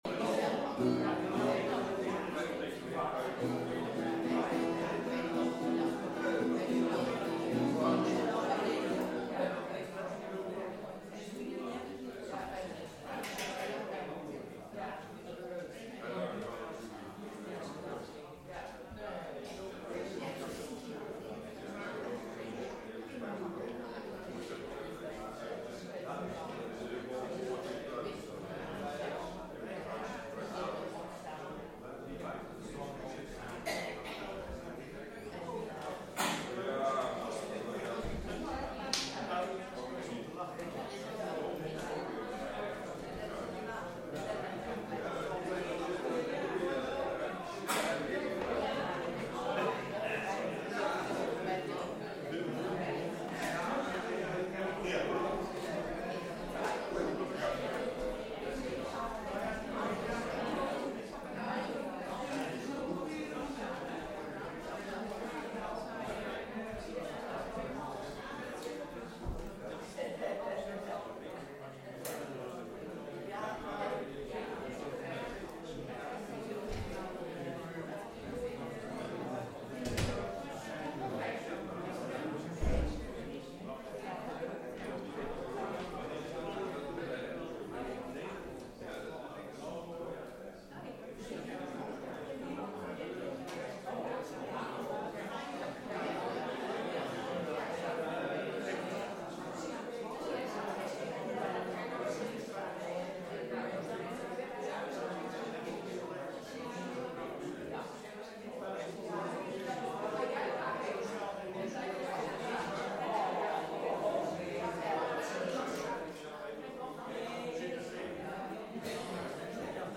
Elke zondag om 10.00 uur komt de gemeente samen in een kerkdienst.
Tijdens de samenkomsten is er veel aandacht voor muziek, maar ook voor het lezen van Gods woord en het overdenken hiervan. We zingen voornamelijk uit Opwekking en de Johannes de Heer bundel.